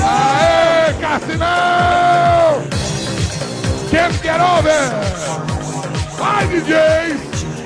Meme do apresentador Gilberto Barros no programa Boa Noite Brasil durante apresentação do grupo Kasino cantando Can't Get Over.